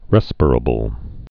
(rĕspər-ə-bəl, rĭ-spīr-)